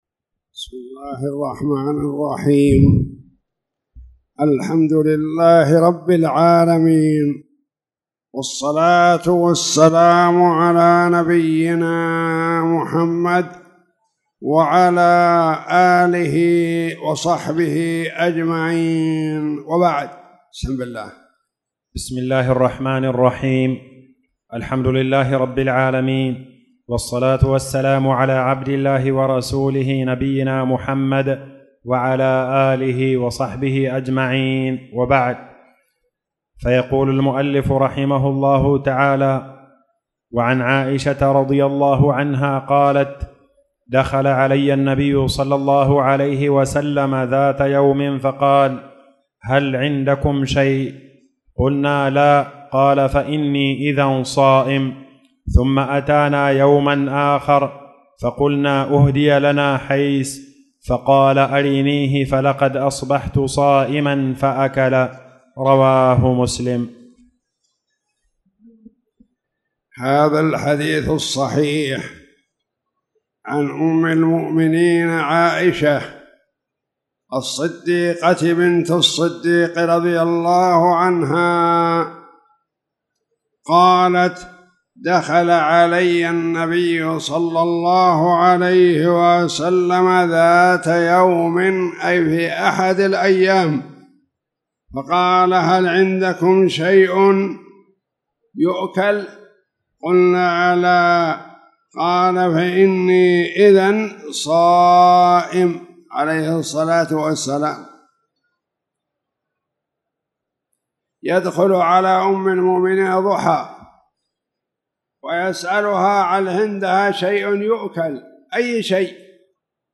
تاريخ النشر ١ شعبان ١٤٣٧ هـ المكان: المسجد الحرام الشيخ